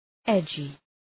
Shkrimi fonetik {‘edʒı}